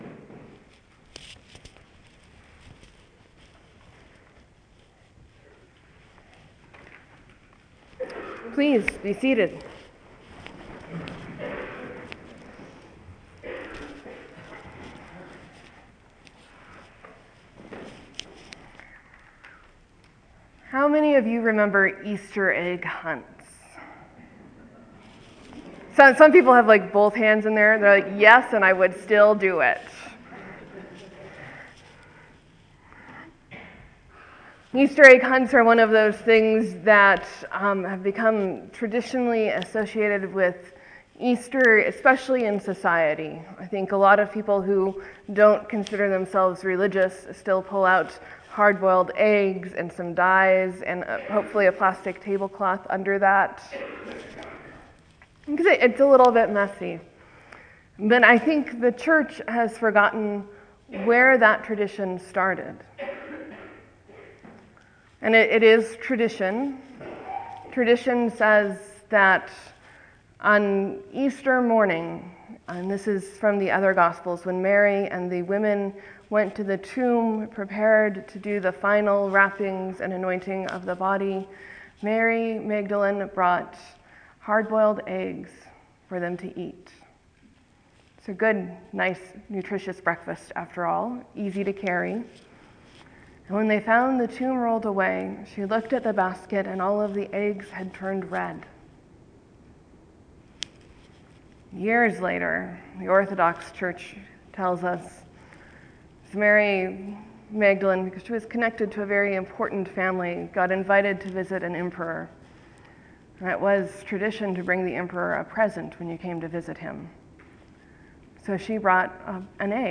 Sermon: Mary runs to tell the apostles, “I have seen the Lord!”